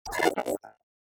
UIGlitch_User interface glitch sound, error, beep, alarm
alarm android application artificial automation beep command computer sound effect free sound royalty free Memes